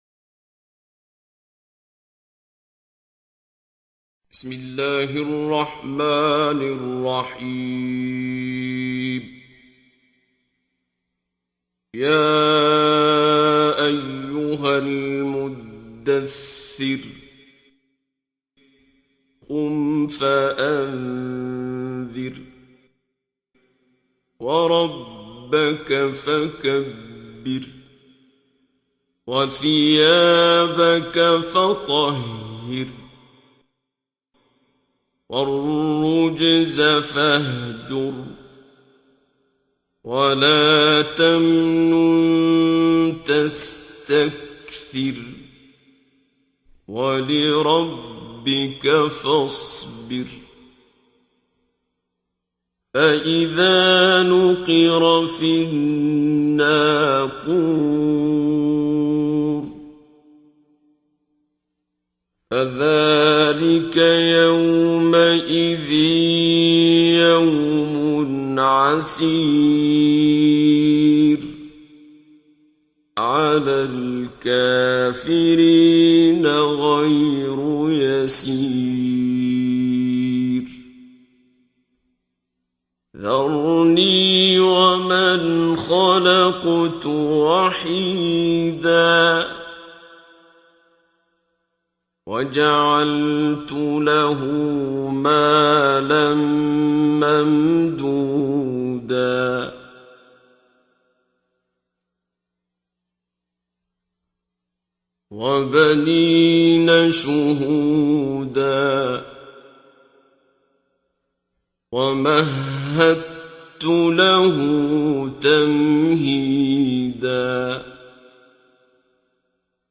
دانلود تلاوت زیبای سوره مدثر آیات 1 الی 56 با صدای دلنشین شیخ عبدالباسط عبدالصمد
در این بخش از ضیاءالصالحین، تلاوت زیبای آیات 1 الی 56 سوره مبارکه مدثر را با صدای دلنشین استاد شیخ عبدالباسط عبدالصمد به مدت 12 دقیقه با علاقه مندان به اشتراک می گذاریم.